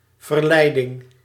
Ääntäminen
Synonyymit aanvechting temptatie verzoeking verlokking Ääntäminen Tuntematon aksentti: IPA: /vərˈlɛidɪŋ/ Haettu sana löytyi näillä lähdekielillä: hollanti Käännös 1.